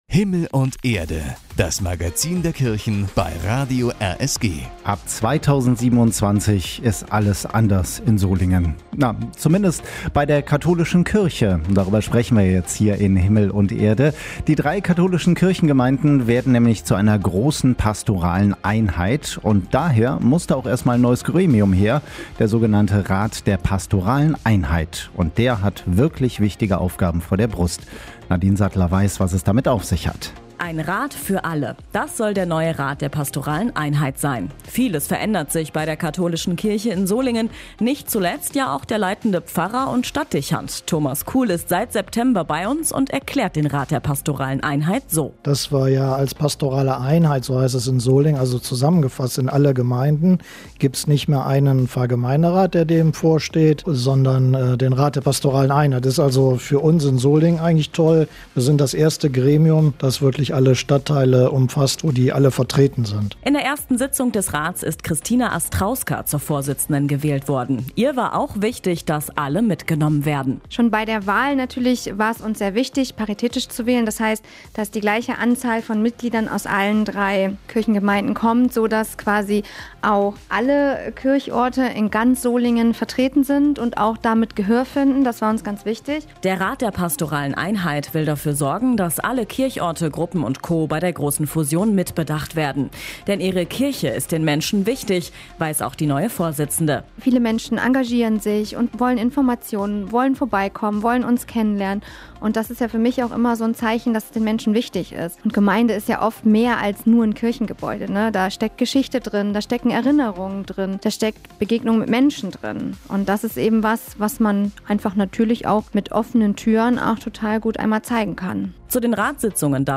Ab 2027 bündeln die drei katholischen Gemeinden in Solingen ihre Kräfte in einer großen pastoralen Einheit. Der neu gegründete Rat der pastoralen Einheit übernimmt dabei zentrale Aufgaben und stellt die Weichen für die Zukunft der Kirche vor Ort. (Beitrag vom 22.02.2026)